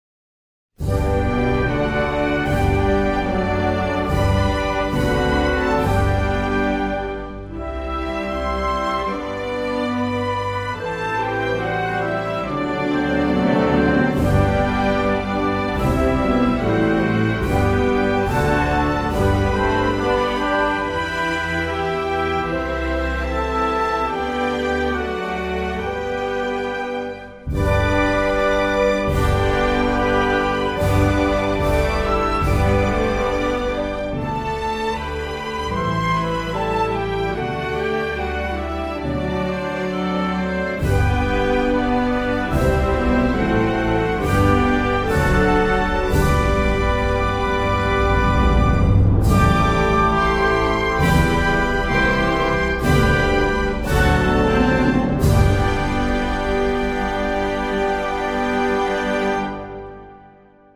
石梁趙民主共和的國國歌奧運版.mp3